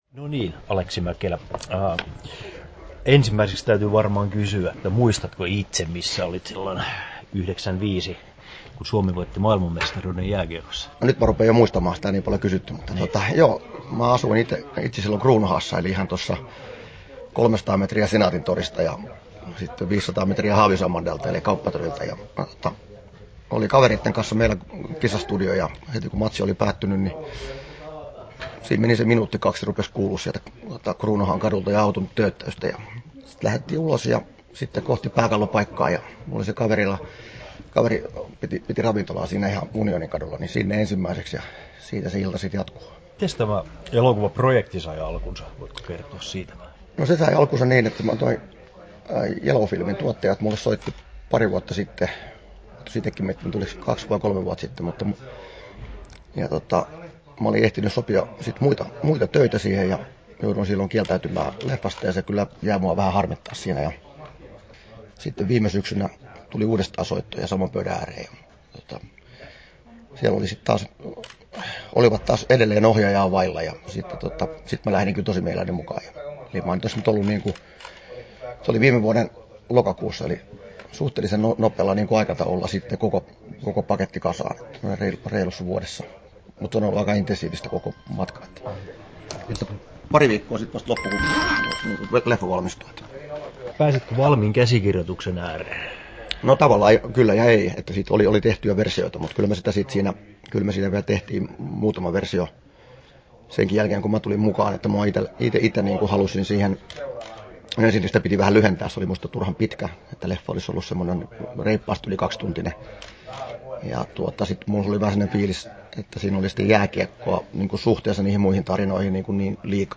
Aleksi Mäkelä ja 95 • Haastattelut
Turku Toimittaja